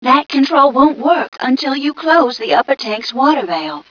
mission_voice_m3ca036.wav